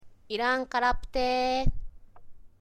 例えば、イランカラ プ テの小さな「プ」は、日本語で札幌（サッポロ）と言うときの「ッ」のように、くちびるを閉じた状態の音を表しています。
irankarapte-sound.mp3